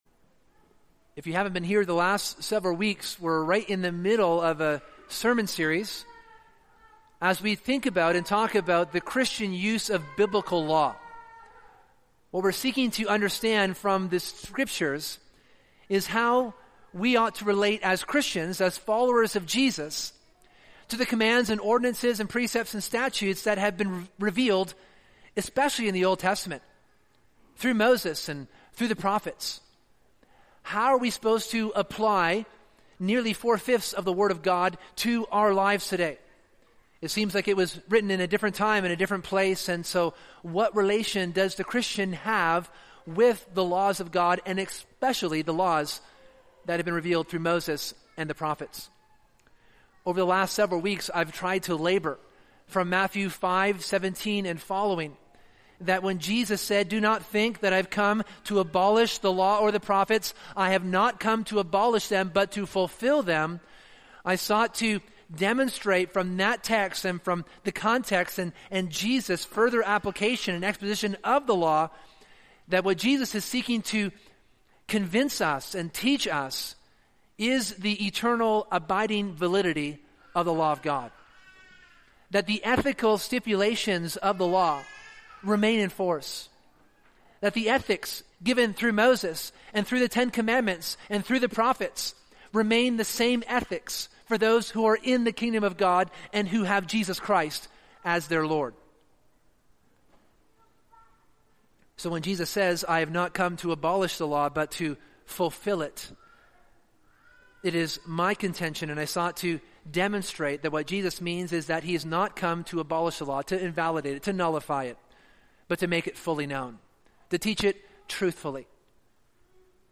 This tension is resolved by considering the Bible’s own distinctions within the law between laws of morality and laws of ceremony. As such, this sermon teaches and defends the reformed distinctions of moral and ceremonial law.